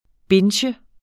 Udtale [ ˈbentjə ]